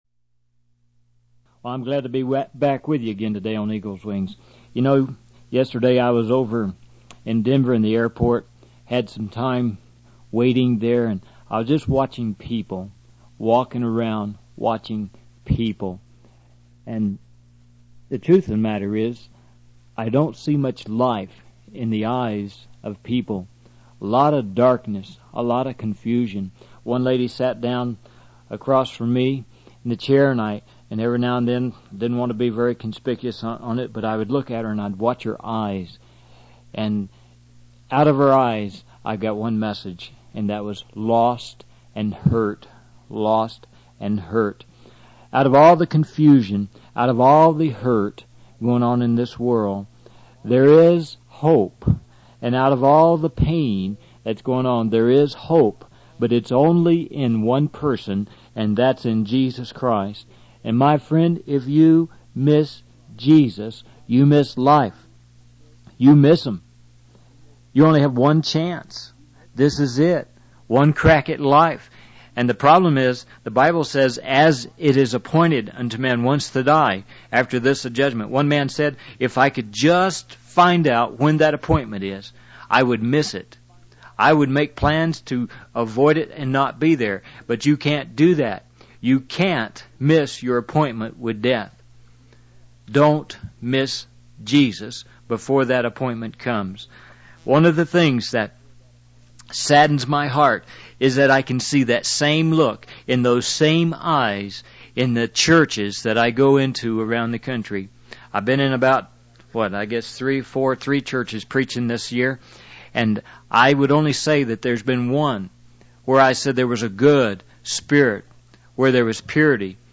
In this sermon, the speaker reflects on the lack of life and hope he sees in people's eyes, emphasizing that the only source of hope is Jesus Christ. He warns that missing Jesus means missing out on life and facing judgment. The speaker urges listeners to accept Jesus as their Savior and not to ignore the Holy Spirit's attempts to lead them to Him.